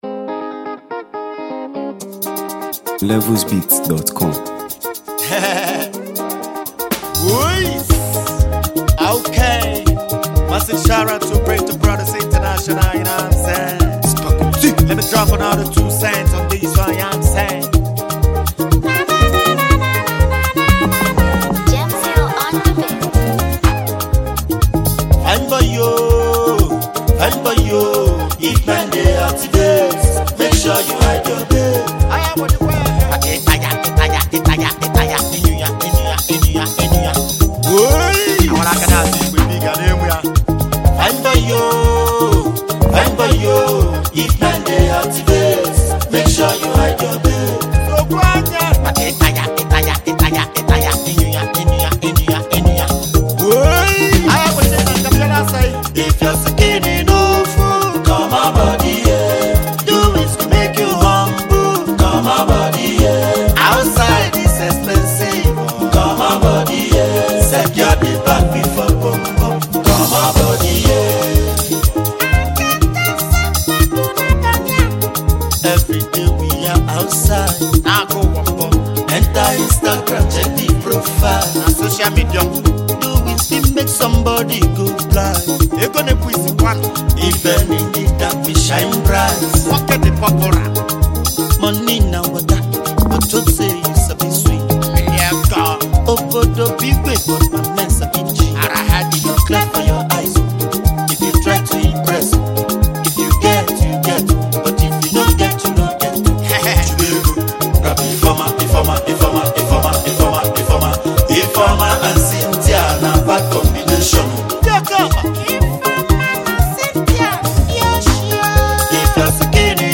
energetic delivery